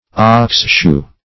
Search Result for " oxshoe" : The Collaborative International Dictionary of English v.0.48: Oxshoe \Ox"shoe`\, n. A shoe for oxen, consisting of a flat piece of iron nailed to the hoof.